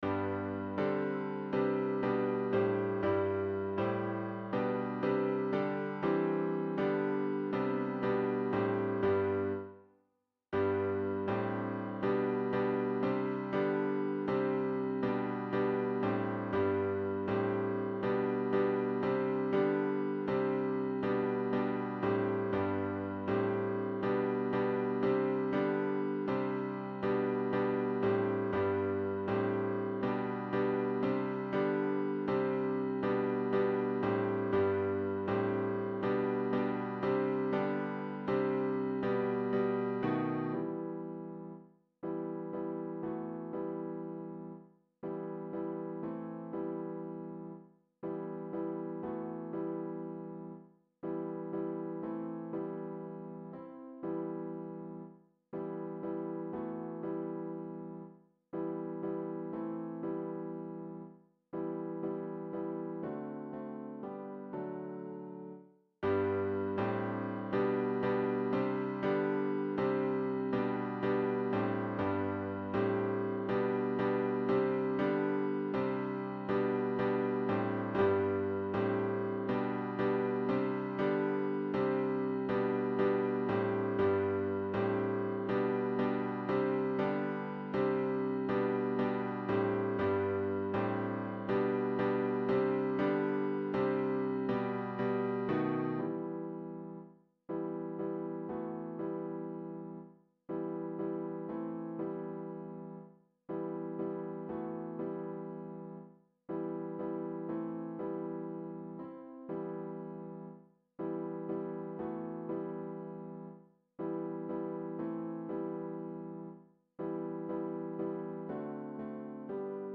Nos primeiros sete compases terás que quedar en silencio, xa que é a introdución do piano.
Acompanamento_piano.mp3